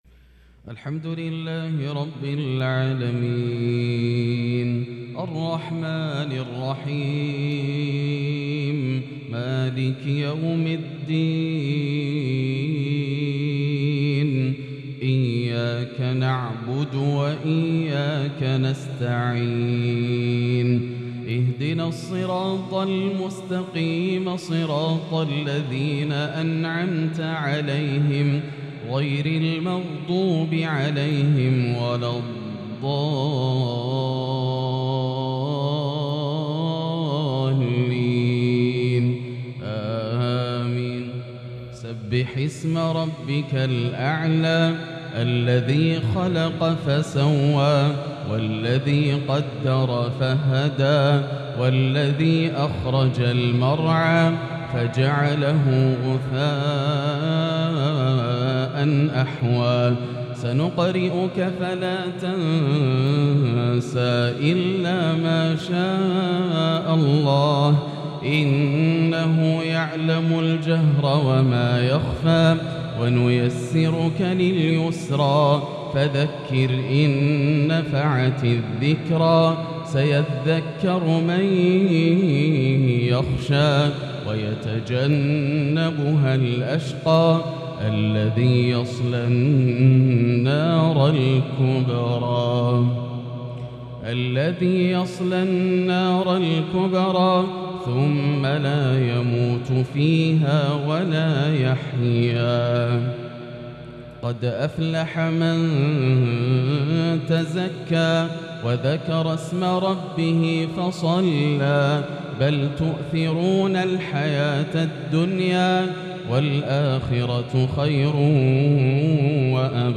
مغرب الأحد 5-7-1443هـ سورة الأعلى | Maghrib prayer from Surah Al-Ala 6-2-2022 > 1443 🕋 > الفروض - تلاوات الحرمين